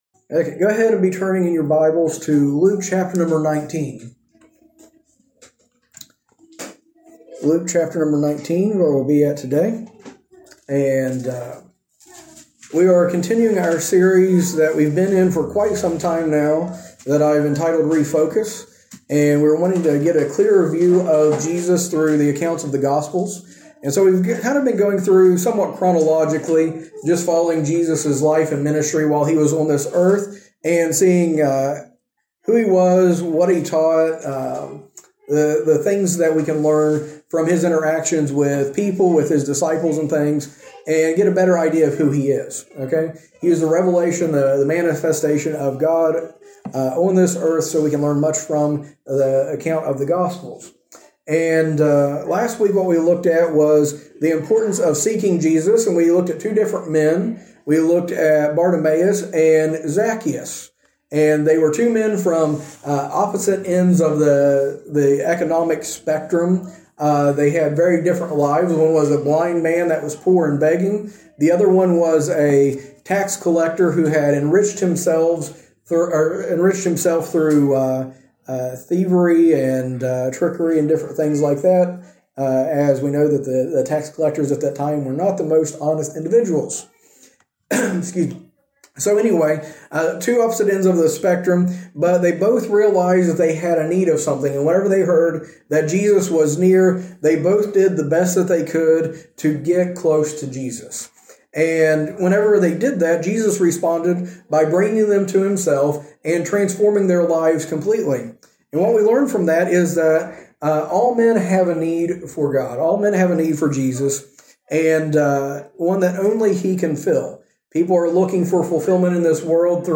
A message from the series "Refocus on Christ."